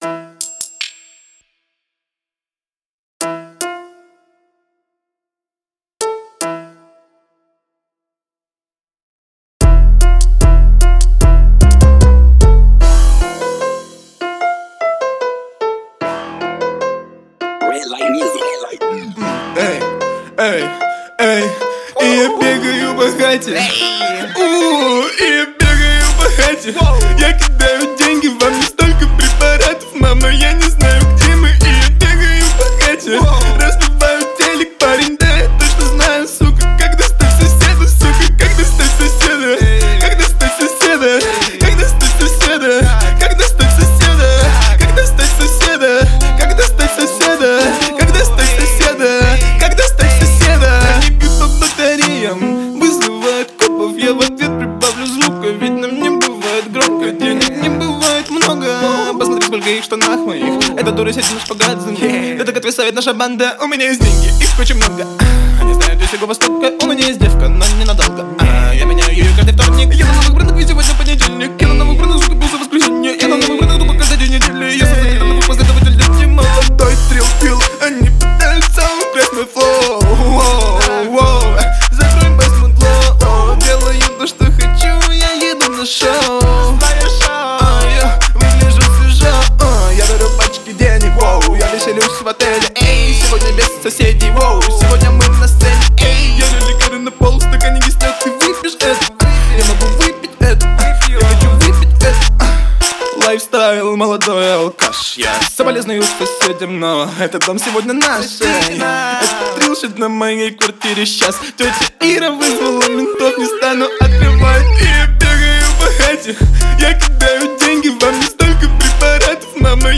Alkuperäinen ääni